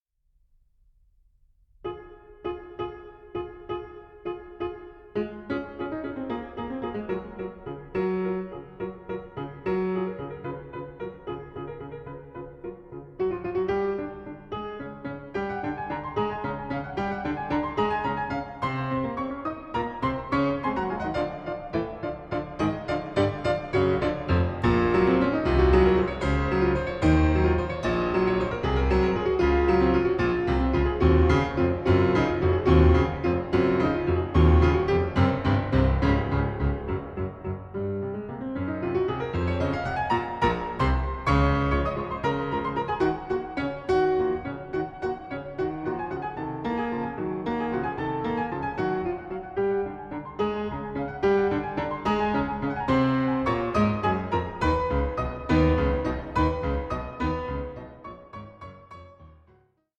in E-Flat Minor: Fugue 2:29